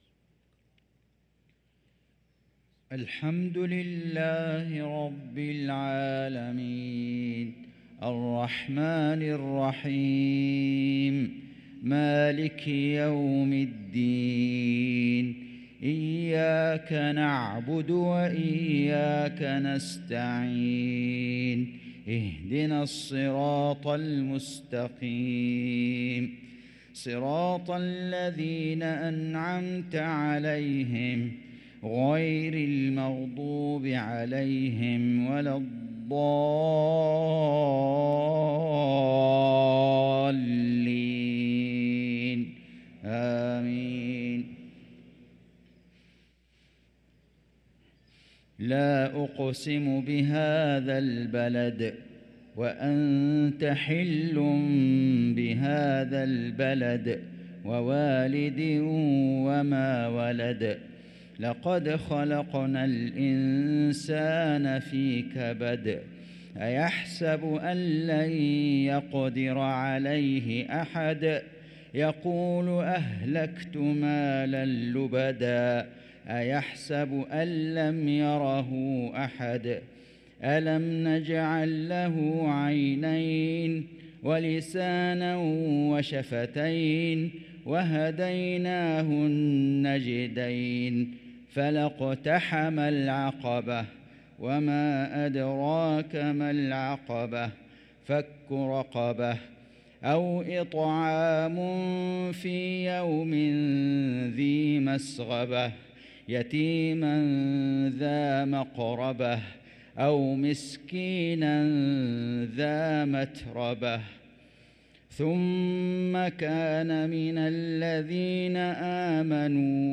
صلاة المغرب للقارئ فيصل غزاوي 15 صفر 1445 هـ
تِلَاوَات الْحَرَمَيْن .